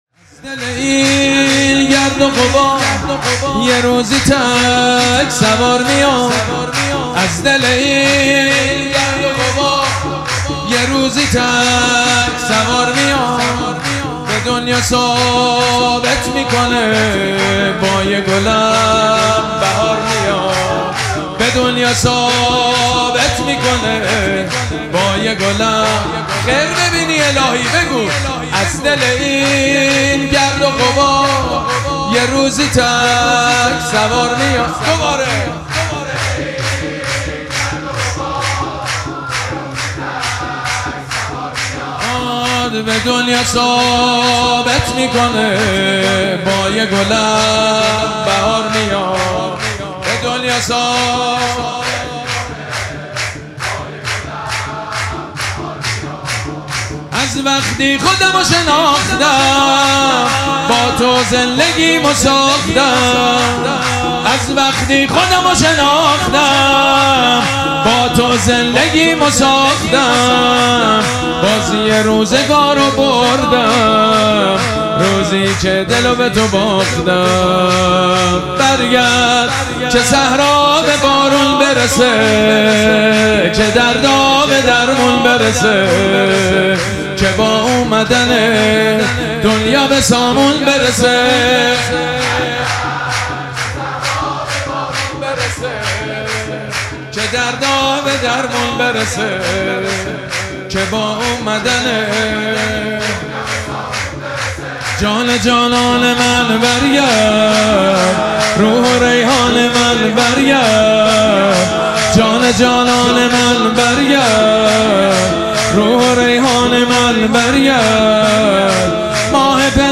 مراسم جشن ولادت حضرت صاحب الزمان (عج)
سرود
مداح